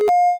startup.ogg